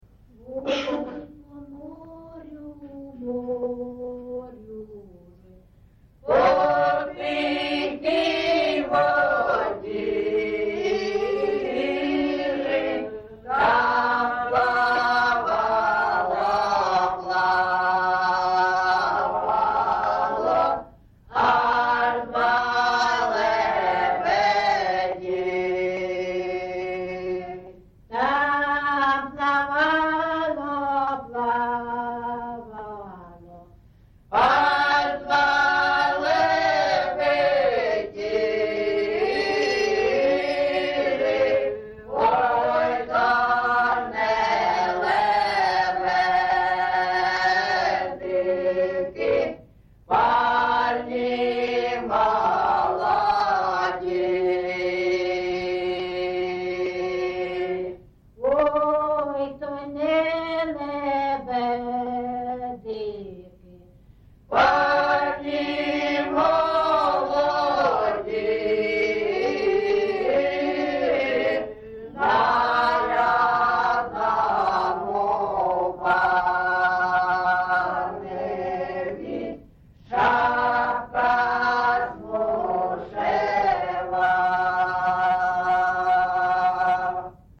GenrePersonal and Family Life
Recording locationAndriivka, Velykа Novosilka district, Donetsk obl., Ukraine, Sloboda Ukraine